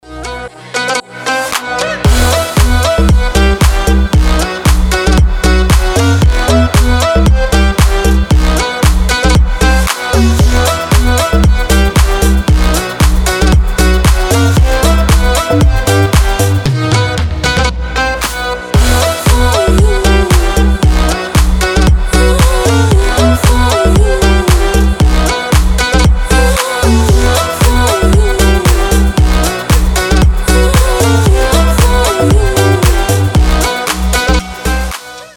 • Качество: 320, Stereo
ритмичные
восточные мотивы
dance
красивый женский голос
house